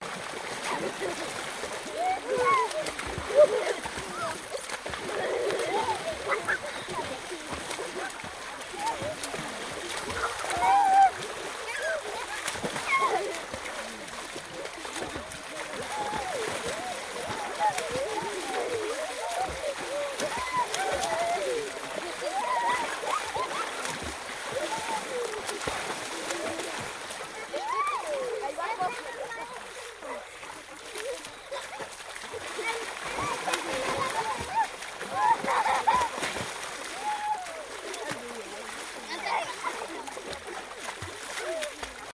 Звуки детского сада
Дети весело плещутся в бассейне